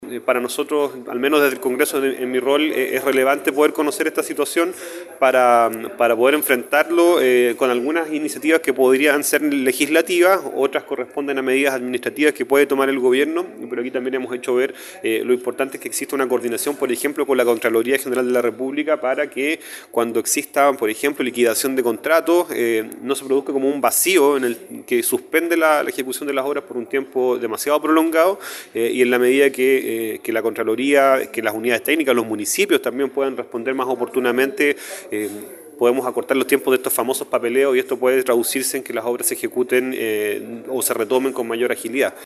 Consejeros regionales y parlamentarios se reúnen en torno a obras paralizadas En tanto, el Diputado Jaime Sáez, Presidente de la Comisión de Obras Públicas de la Cámara Baja, relevó la importancia de que exista una coordinación con la Contraloría para abordar este tema, independientemente de los avances y ajustes que se puedan realizar en materia legislativa.